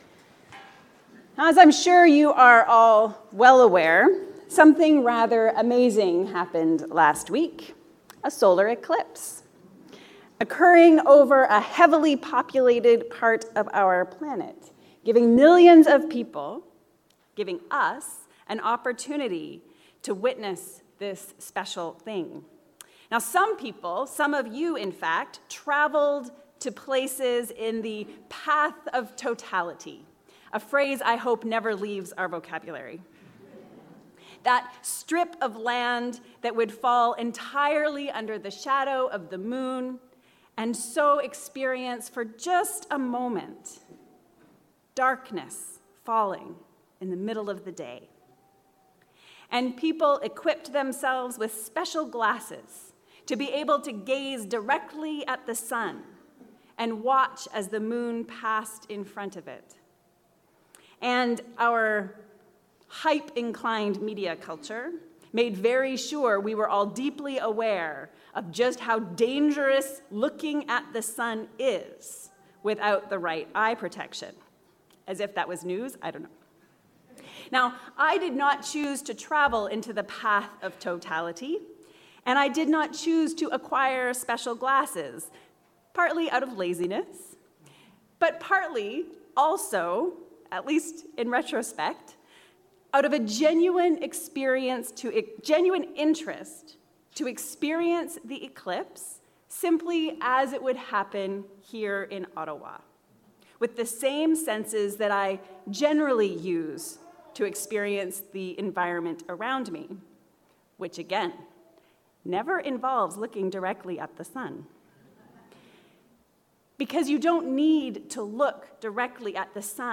Experiencing Resurrection. A sermon for the 3rd Sunday of Easter